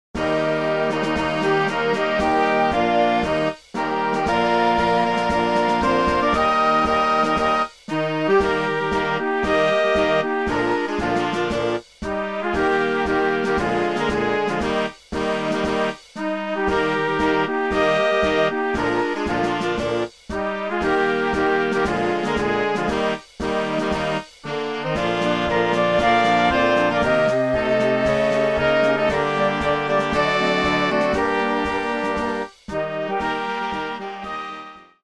marsze